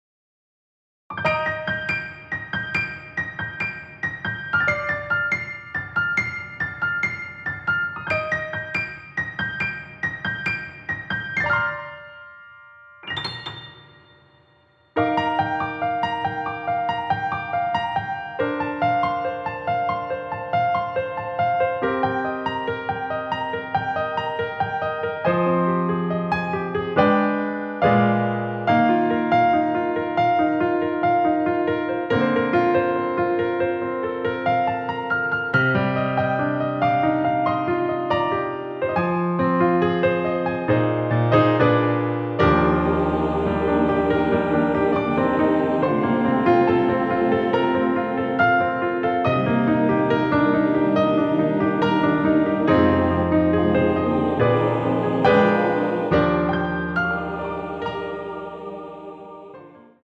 원키에서(+2)올린 MR입니다.
Bb
앞부분30초, 뒷부분30초씩 편집해서 올려 드리고 있습니다.